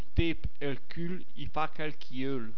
Se hai un browser che supporta i file .wav, cliccando sui proverbi scritti in bergamasco potrai ascoltarne anche la pronuncia, almeno per i primi 80 .